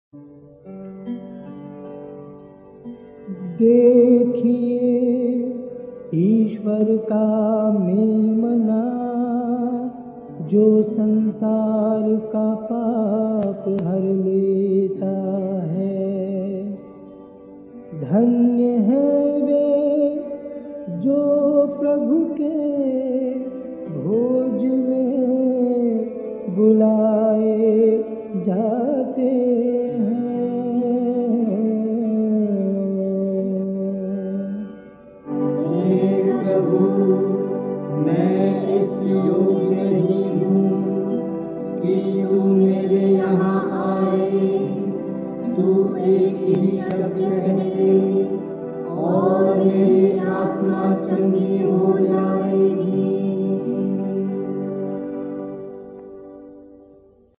Directory Listing of mp3files/Hindi/Hymns/Fr. George Proksh SVD/ (Hindi Archive)